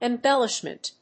音節em･bel･lish･ment発音記号・読み方ɪmbélɪʃmənt
• / ‐mənt(米国英語)
• / eˈmbelɪʃmʌnt(英国英語)